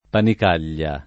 [ panik # l’l’a ]